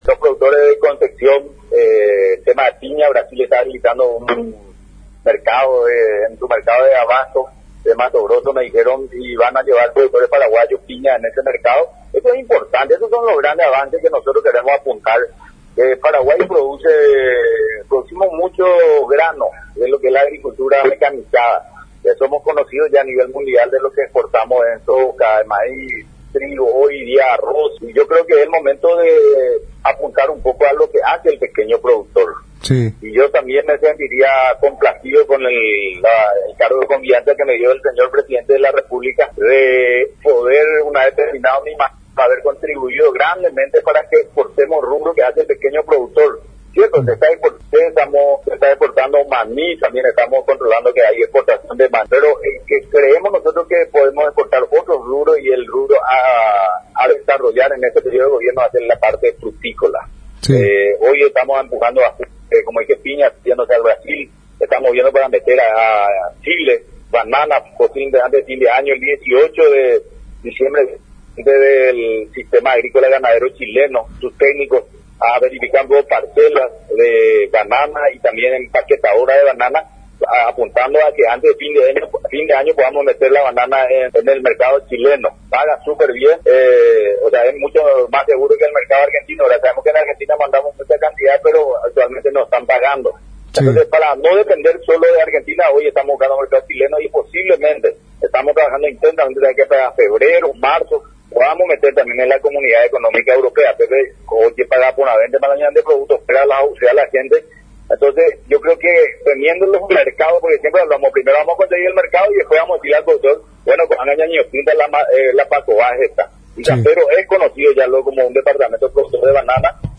Por otro lado, el presidente del SENAVE, Ing. Pastor Soria, se refirió sobre las acciones importantes que lleva adelante por la institución a su cargo, resaltando al respecto los avances en la calidad y sanidad de nuevos rubros de exportación a los exigentes mercados internacionales.